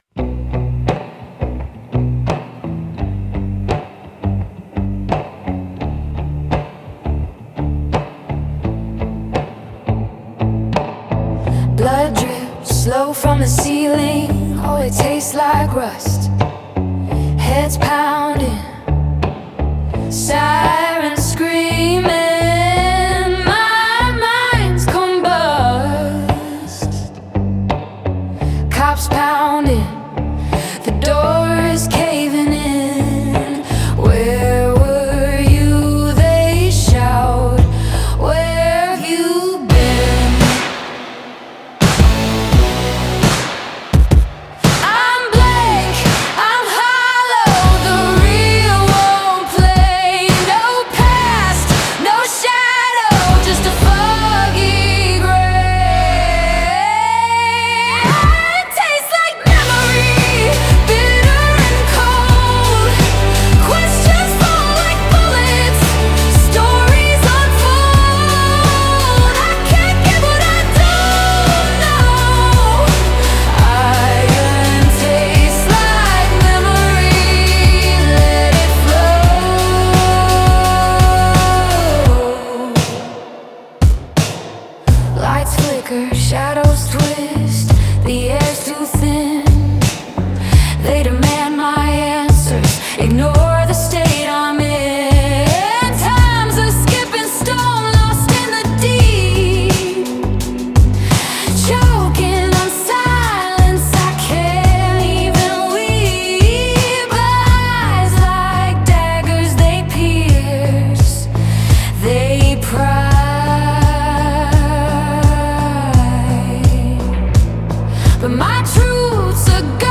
Phenomenal lyrics my friend especially that chorus, love the drive of the musical arrangement, the beat is almost trance like.